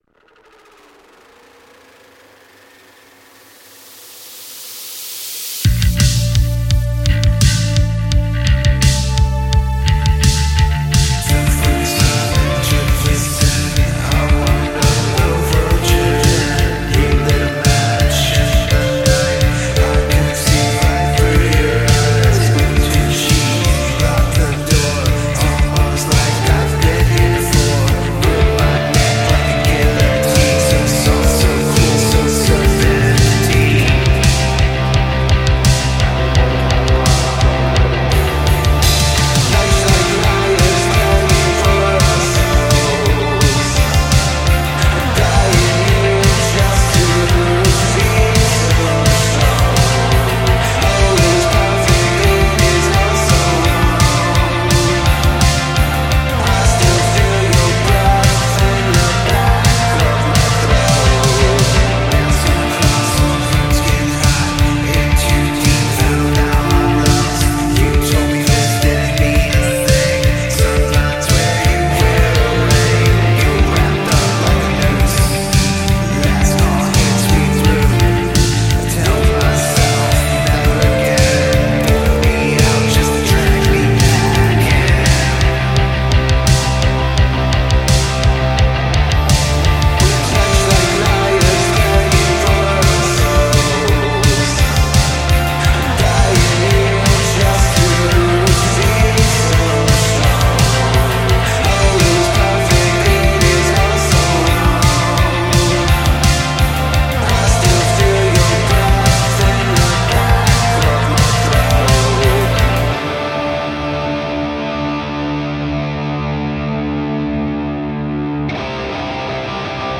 Gothic Rock, Dreampop, Alternative